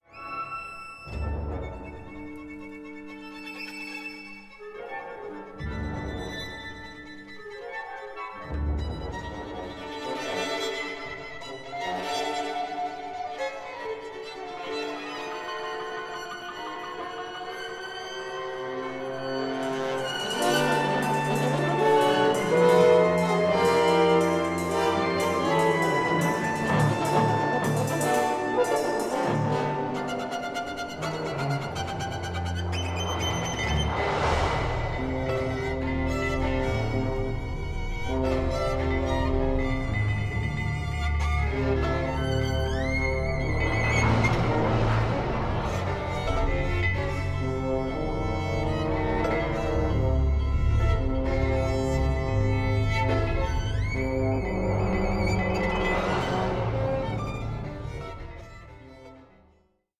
A COSMIC, ADVENTUROUS PAIRING OF VIOLIN CONCERTOS